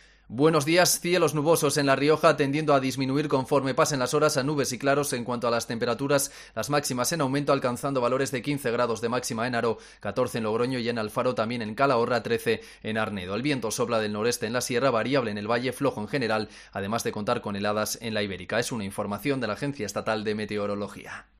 AUDIO. Pronóstico del tiempo con la Agencia Estatal de Meteorología